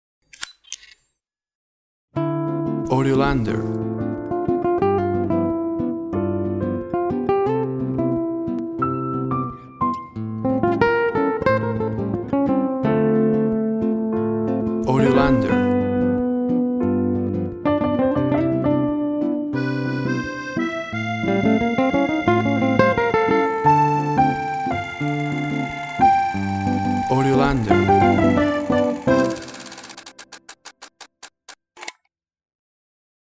Jingle for TV shows, cheerful with camera sounds.
Tempo (BPM) 90